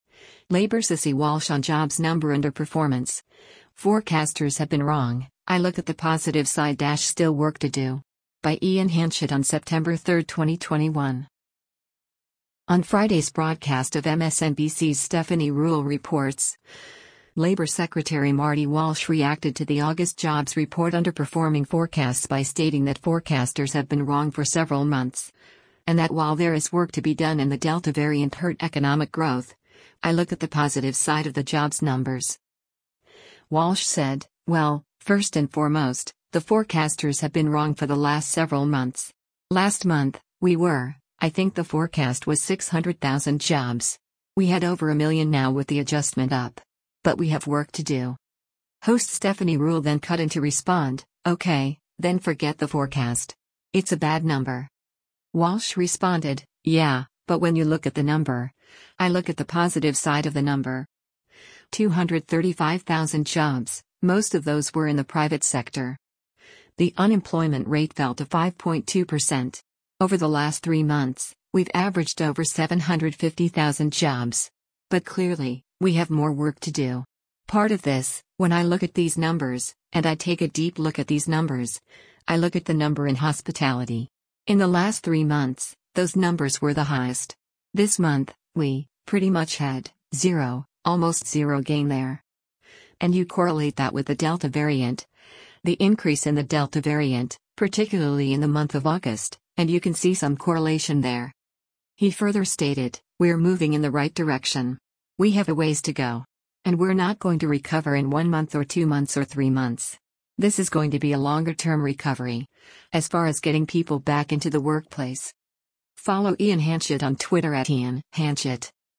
On Friday’s broadcast of MSNBC’s “Stephanie Ruhle Reports,” Labor Secretary Marty Walsh reacted to the August jobs report underperforming forecasts by stating that forecasters have been wrong for several months, and that while there is work to be done and the Delta variant hurt economic growth, “I look at the positive side of” the jobs numbers.
Host Stephanie Ruhle then cut in to respond, “Okay, then forget the forecast. It’s a bad number.”